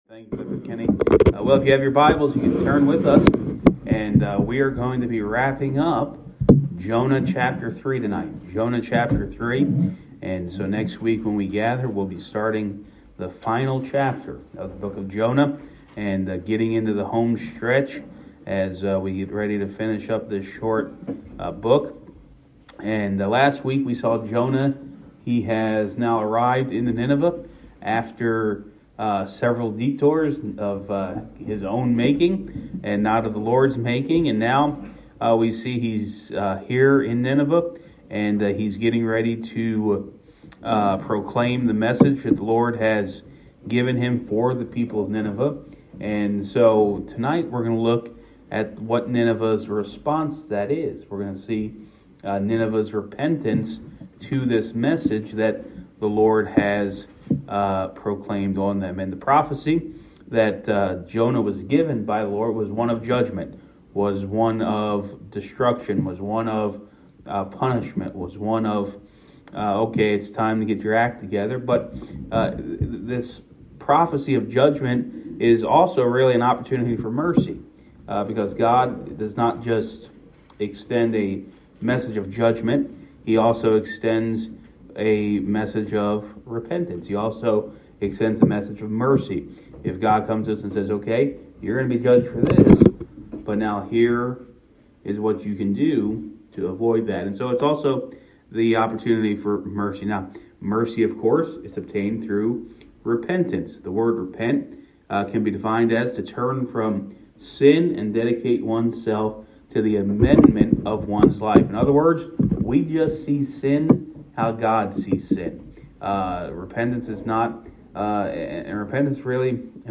Wednesday Evening Bible Study
Guest Speaker